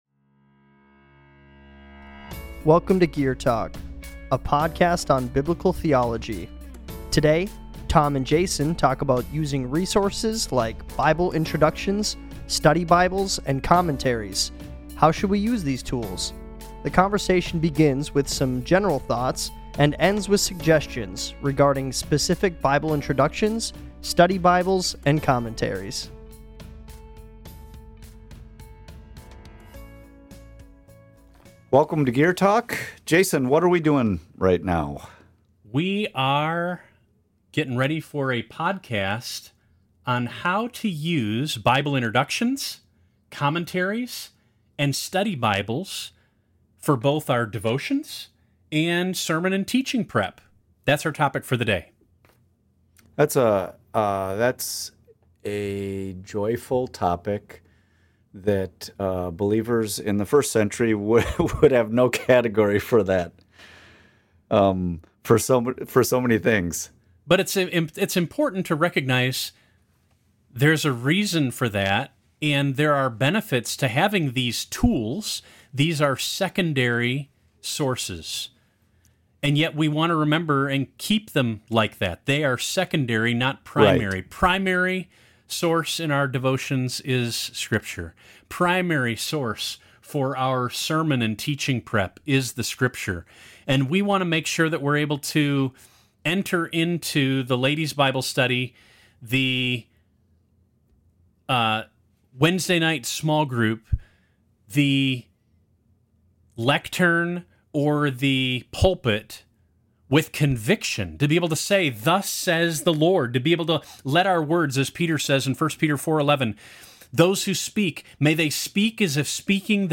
How should we use these tools? The conversation begins with some general thoughts and ends with suggestions regarding specific Bible introductions, study Bibles, and commentaries.